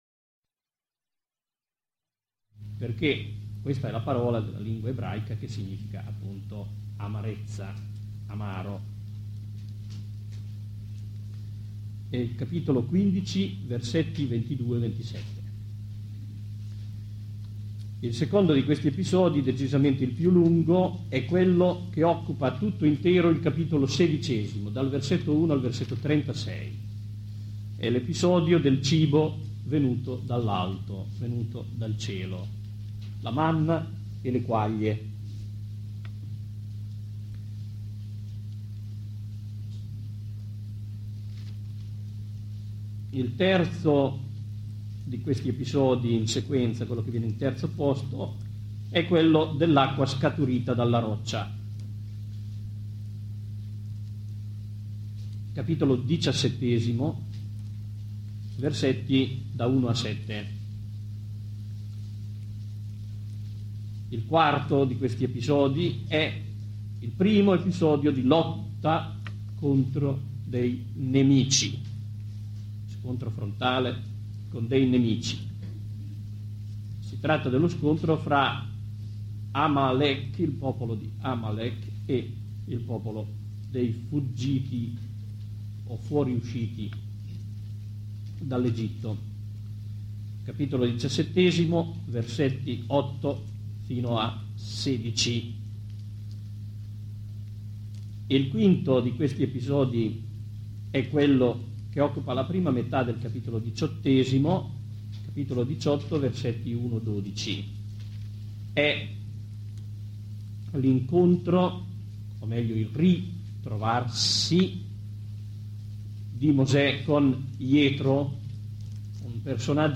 Contributi audio - registrazioni delle lezioni Argomento File audio 1 1° Lezione Click to download in MP3 format (27.64MB) 2 2 ° Lezione Click to download in MP3 format (41.92MB) 3 colspan="2" />3° Lezione File don't exists.